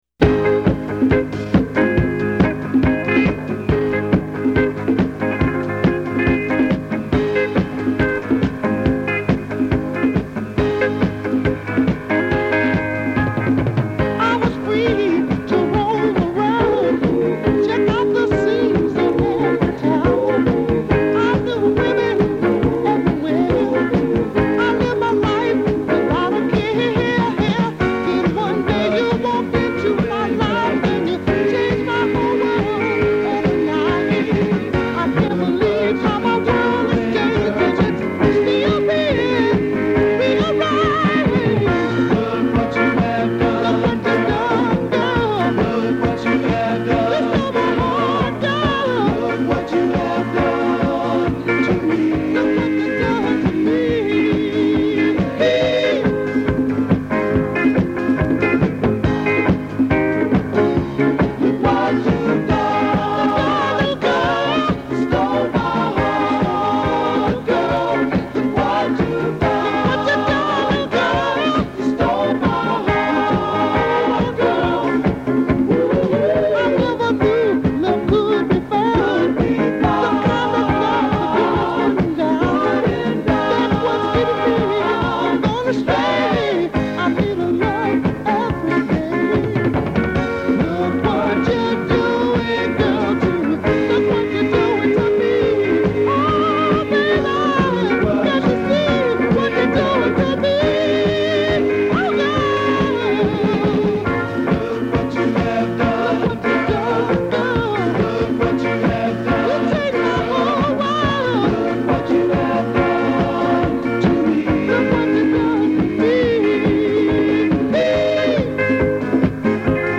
afro-styled conga drummer extraordinaire.